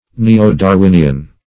neo-darwinian - definition of neo-darwinian - synonyms, pronunciation, spelling from Free Dictionary
neo-darwinian.mp3